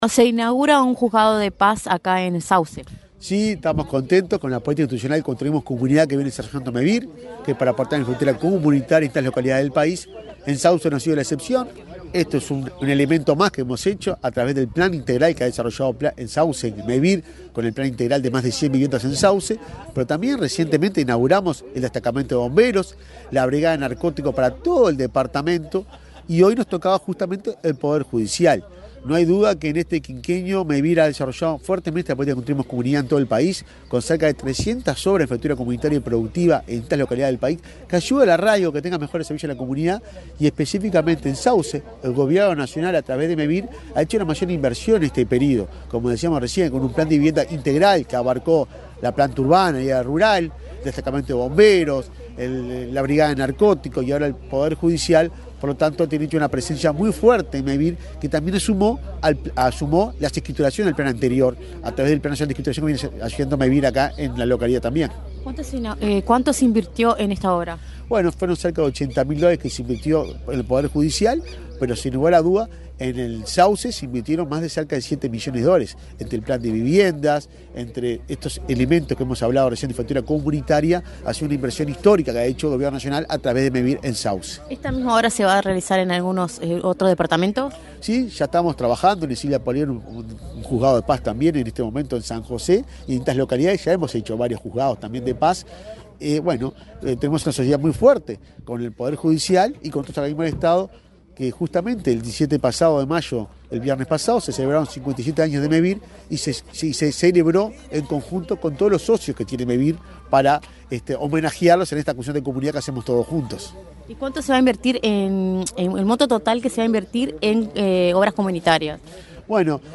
Entrevista al presidente de Mevir, Juan Pablo Delgado
El presidente de Mevir, Juan Pablo Delgado, dialogó con Comunicación Presidencial, en Sauce, Canelones, antes de participar en la inauguración de un